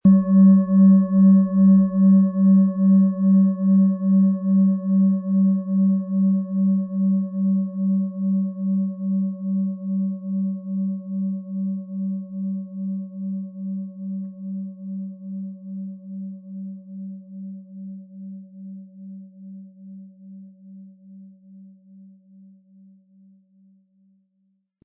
Schon beim ersten Anspielen entfaltet sich ihr Klang: Der tiefe, ruhige Grundton ist exakt auf den Tageston abgestimmt – eine Frequenz, die dich erdet, das Gedankenkarussell beruhigt und dich sanft im Hier und Jetzt ankommen lässt.
Es ist eine von Hand getriebene Klangschale, aus einer traditionellen Manufaktur.
• Mittlerer Ton: Pluto